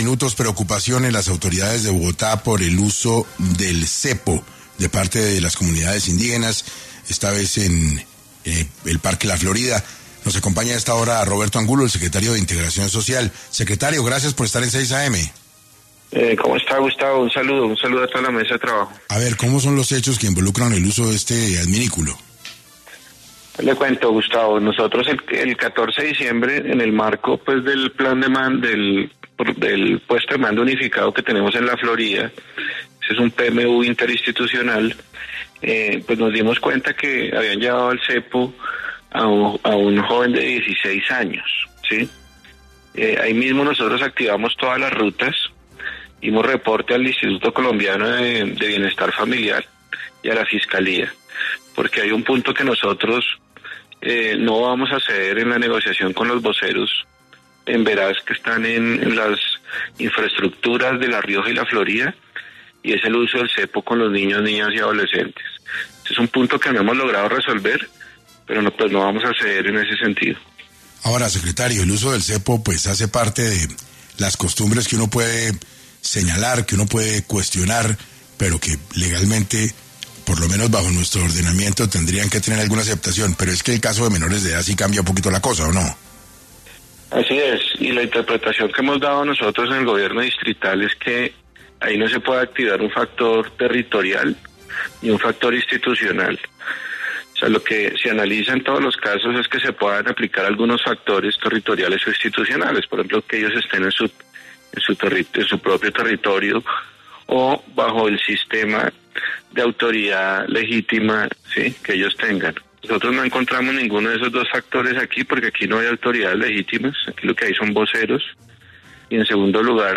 El Secretario de Integración Social de Bogotá, Roberto Angulo aseguró en 6AM que no van a dejar que esta clase de castigos se sigan presentando contra los menores Emberá que se encuentran en la capital.